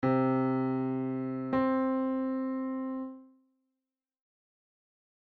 On the piano, play the following:
C - then up an octave - play the next C